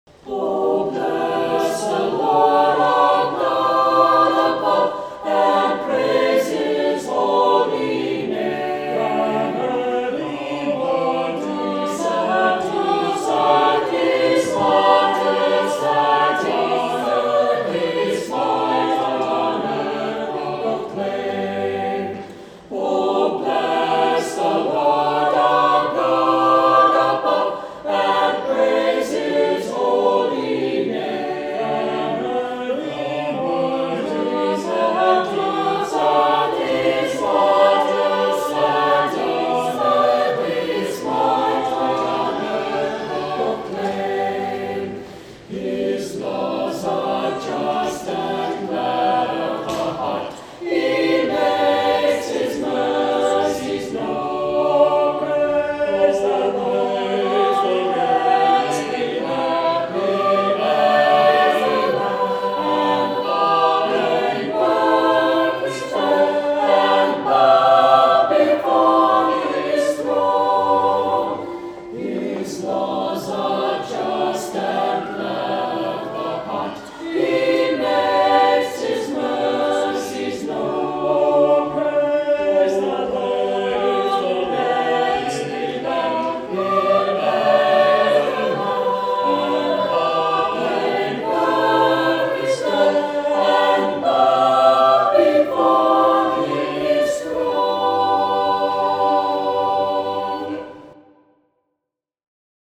Saint Clement Choir Sang this Song
Anthem